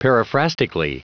Prononciation du mot periphrastically en anglais (fichier audio)
Prononciation du mot : periphrastically